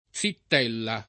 +zit$lla] (meno com. zittella [